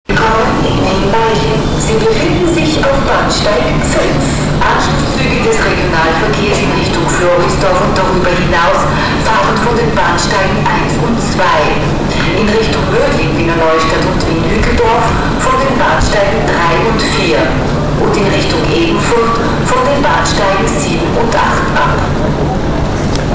WnMl_hlaseni_eska2.WMA